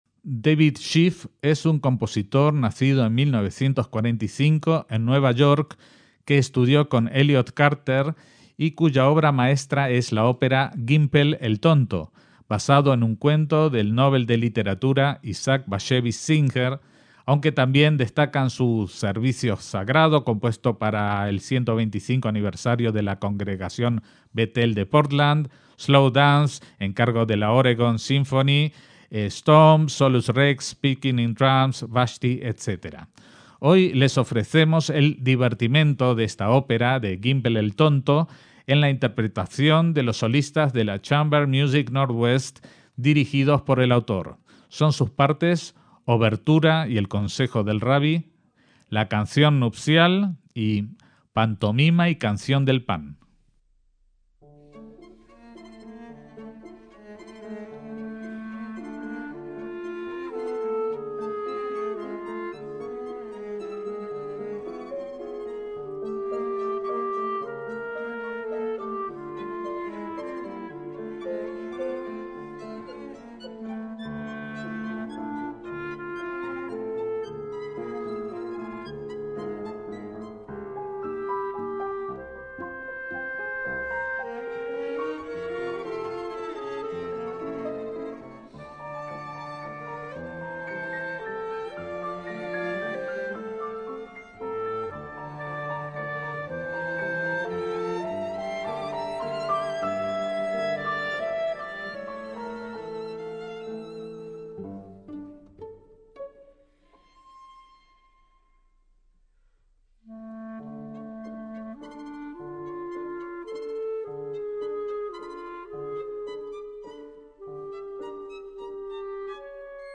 MÚSICA CLÁSICA
clarinete
violín
chelo